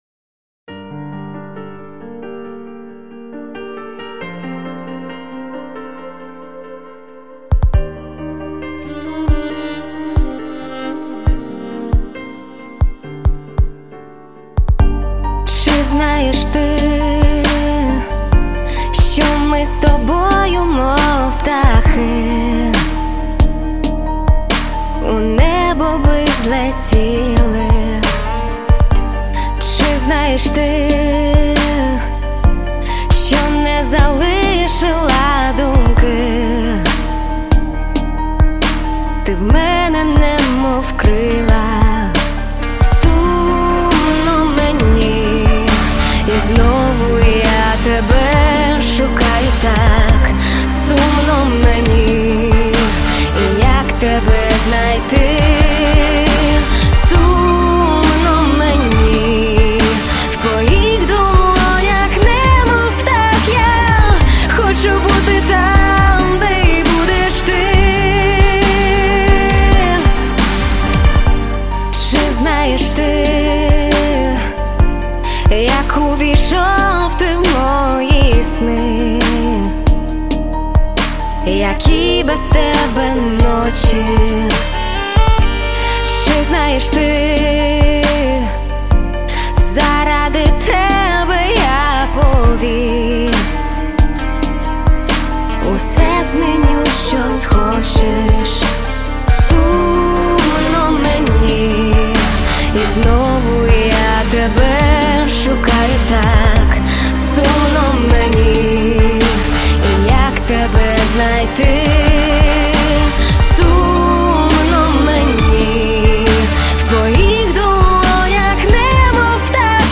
Всі мінусовки жанру Ballad
Плюсовий запис